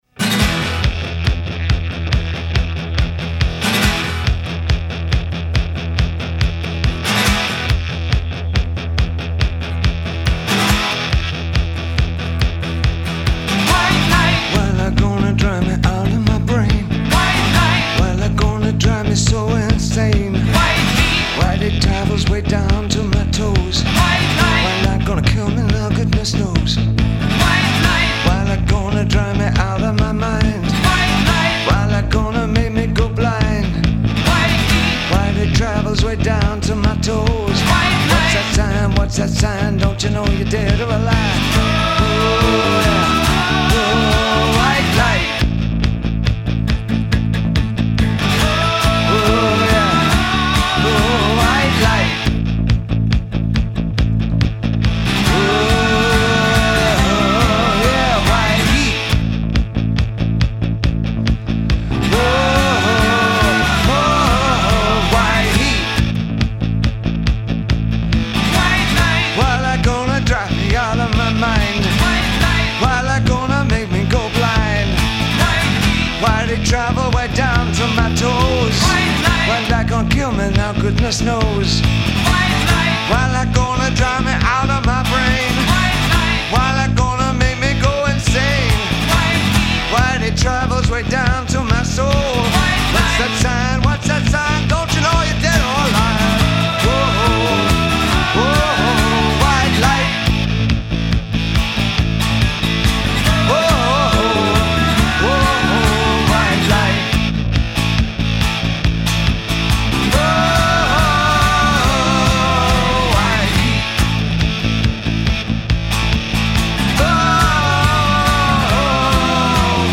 ultra-rare live-in-the- studio performance